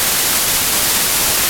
Het drukverloop ziet er dan eerder willekeurig uit.
ruistoon
ruis.wav